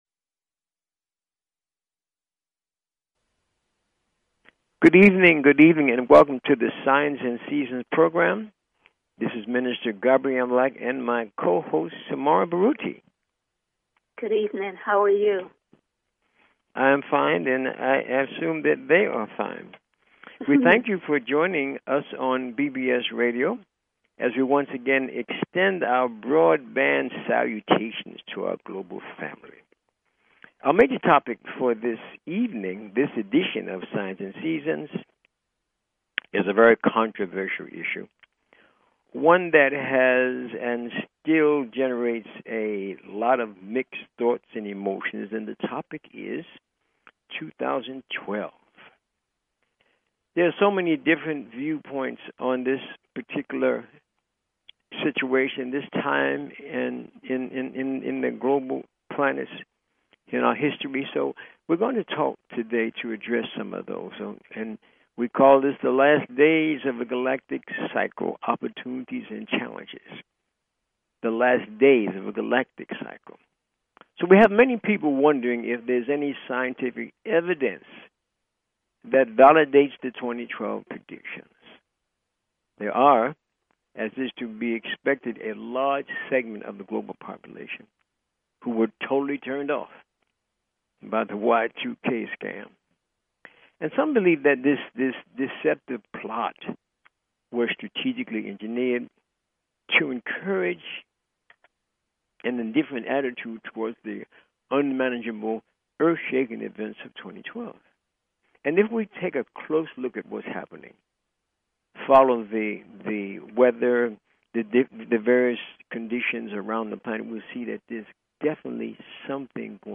Talk Show Episode, Audio Podcast, Signs_and_Seasons and Courtesy of BBS Radio on , show guests , about , categorized as
The Primary objectives of this broadcast are: To ELIMINATE the fearful and superstitious attitudes that many peple have towards Astrology and related "Metaphysical" teachings by providing information on the constructive uses of these ancient sciences. And to demonstrate the practical value of Astrology and Numerology by giving on air callers FREE mini readings of their personal Astro-numerica energy profiles.